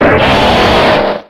infinitefusion-e18 / Audio / SE / Cries / SCEPTILE.ogg